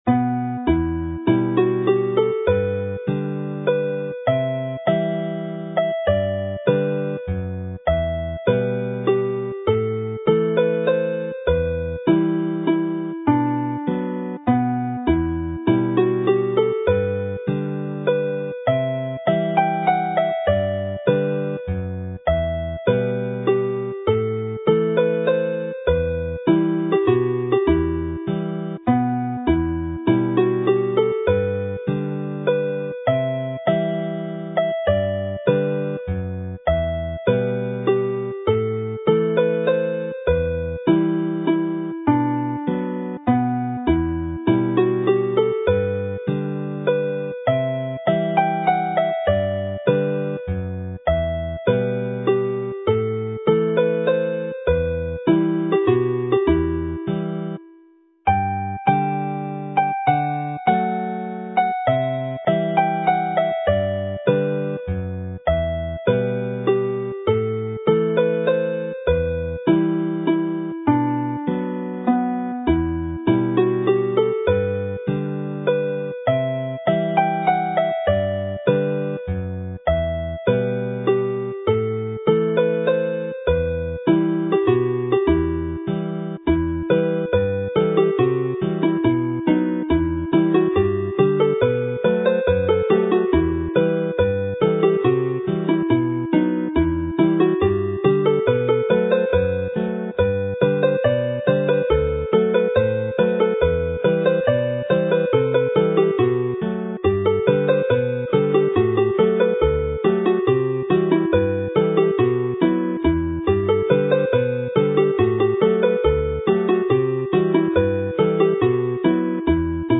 Chwarae'r set - unwaith trwy bob alaw
Play the set - once through each tune